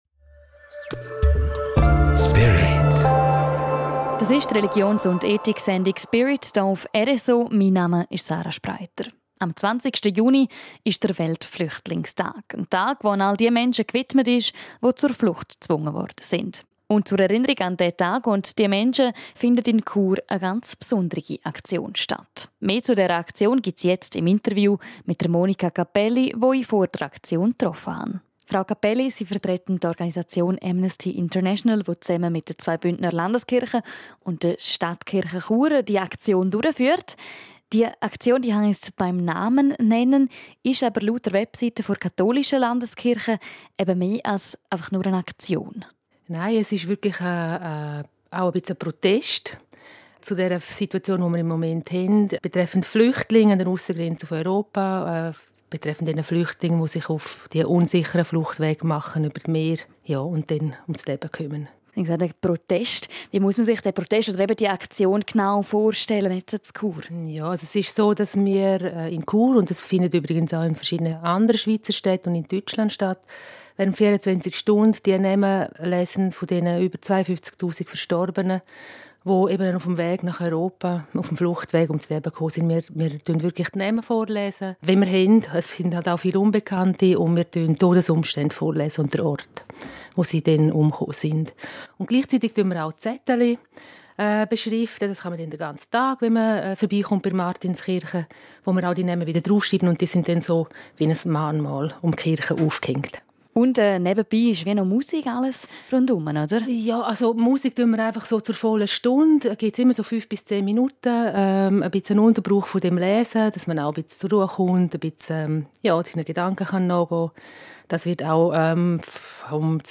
Leben & Freizeit